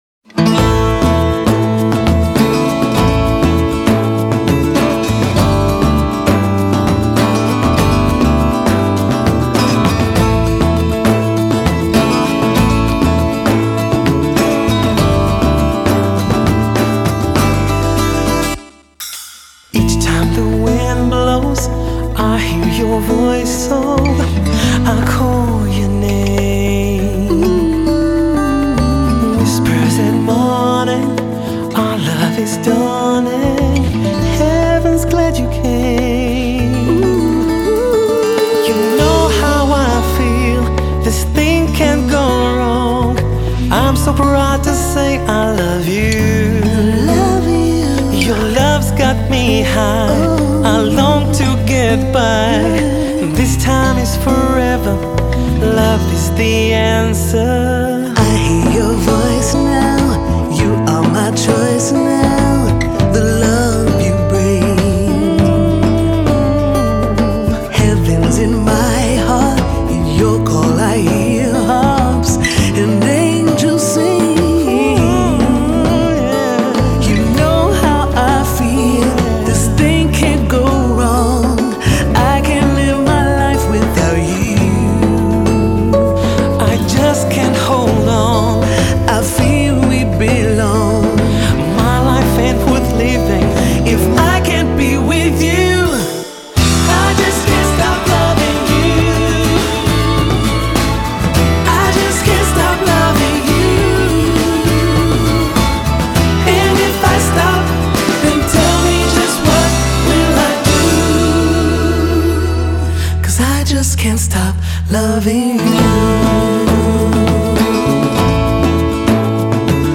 Batteria e Percussioni